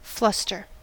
Ääntäminen
IPA : /ˈflʌstə/